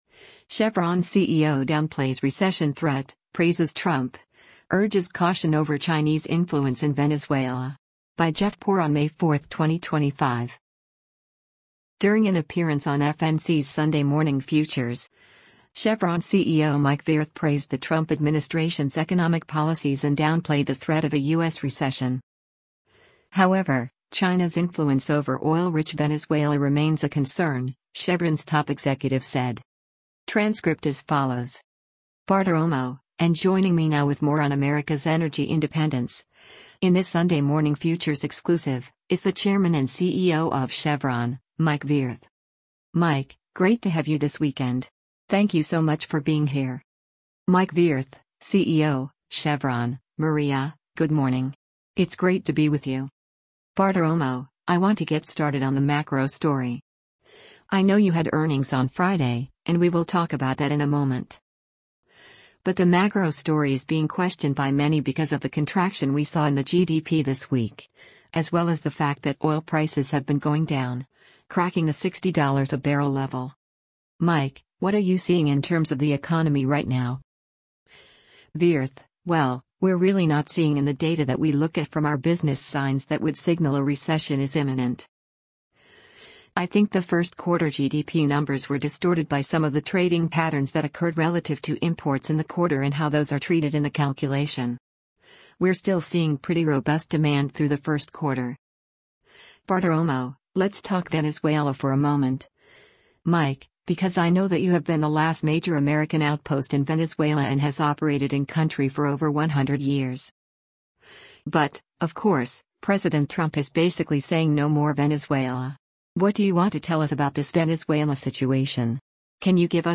During an appearance on FNC’s “Sunday Morning Futures,” Chevron CEO Mike Wirth praised the Trump administration’s economic policies and downplayed the threat of a U.S. recession.